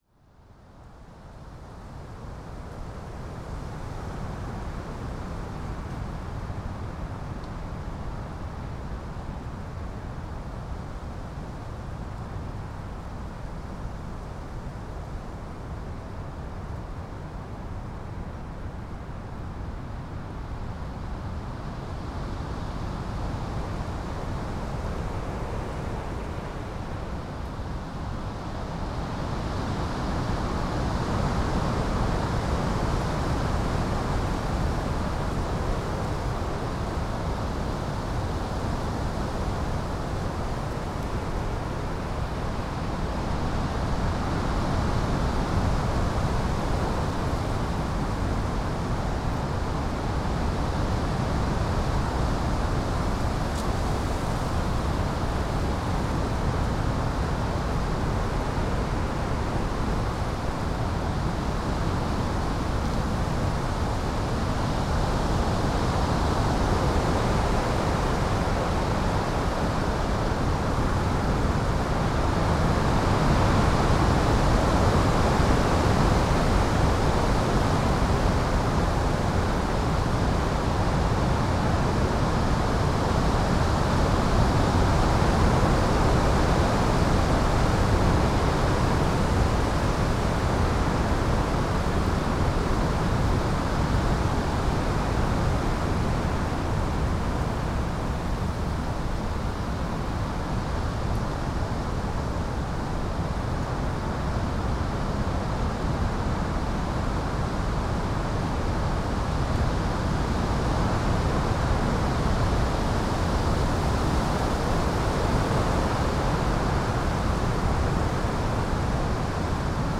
Roaring GALE over wooded valley - Teign Gorge above Fingle Bridge Sound Effect — Free Download | Funny Sound Effects